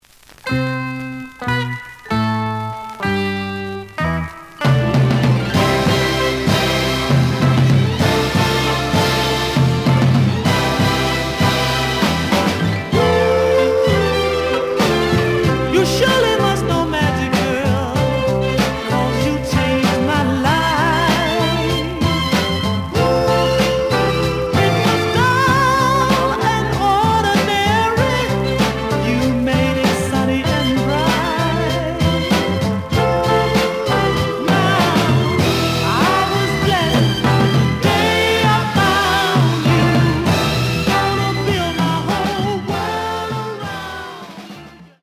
The audio sample is recorded from the actual item.
●Genre: Soul, 60's Soul
Looks good, but slight noise on both sides.